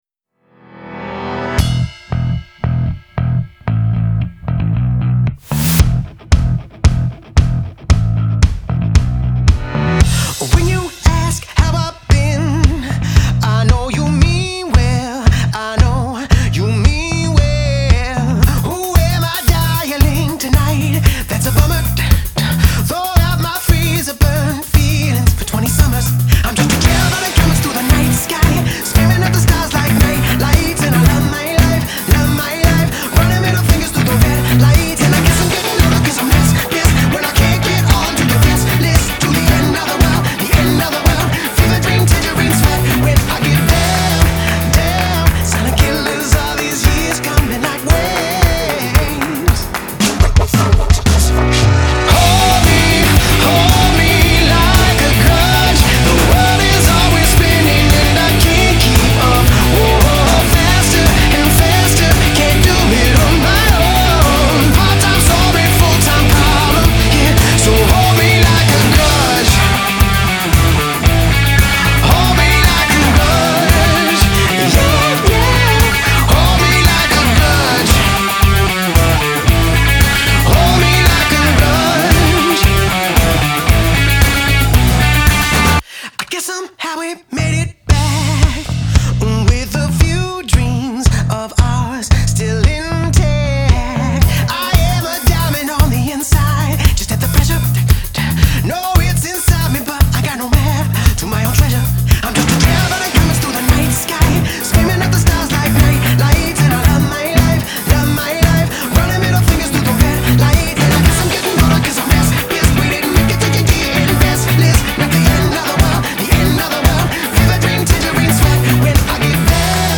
Genre : Pop, Rock